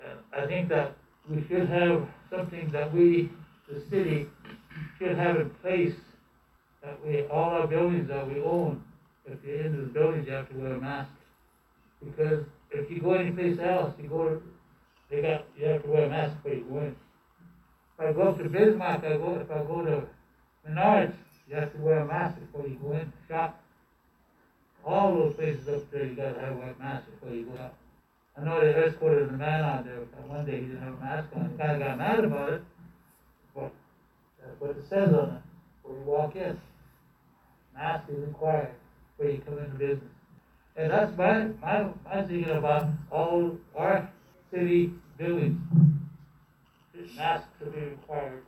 Mobridge City Council discusses mask policies
Council member Tony Yellow Boy recommends signage on city-owned buildings requiring the wearing of masks in order to enter.